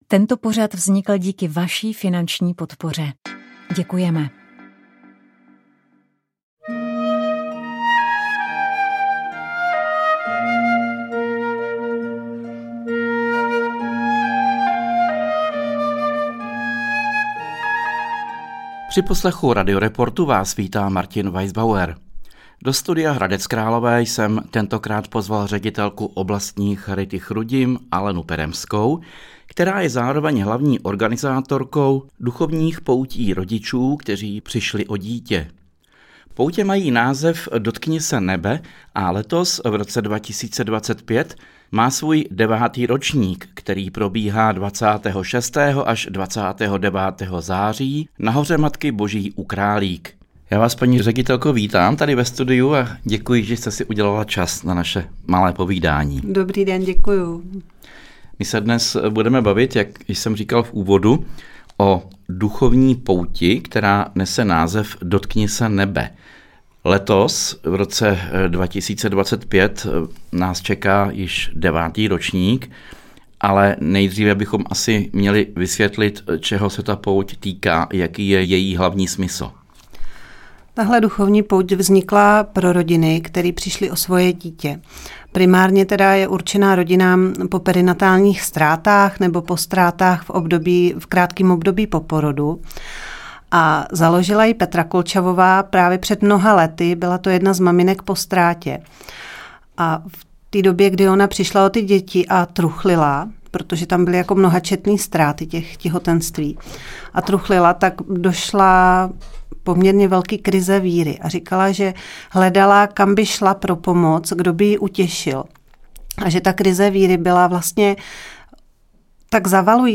V našem pořadu si budeme povídat o tom, jak se k poezii dostal, jaká témata mu jsou nejbližší a jak se postupem věku vyvíjela, kde čerpá inspiraci, a jestli je poezie v dnešní době ještě důležitá. Na ukázku nám přednese i několik básní ze své tvorby.